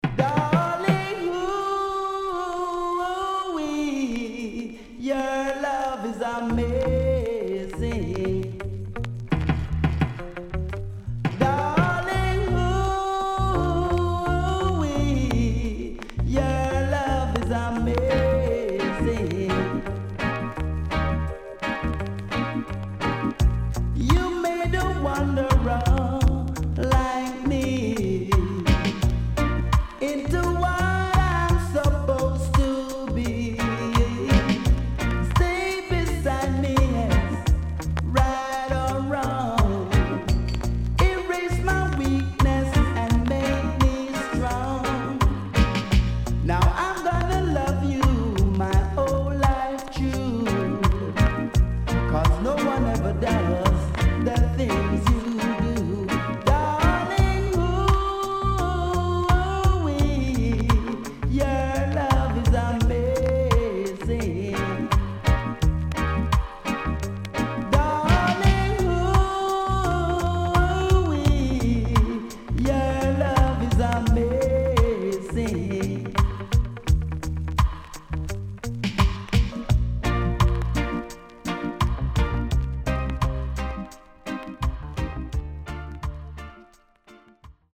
HOME > Back Order [DANCEHALL 7inch]
SIDE A:所々チリノイズがあり、少しプチノイズ入ります。